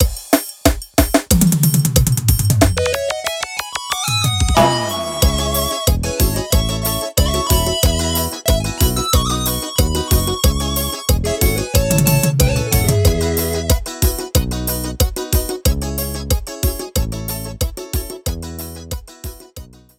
• Demonstrativo Xote:
• São todos gravados em Estúdio Profissional, Qualidade 100%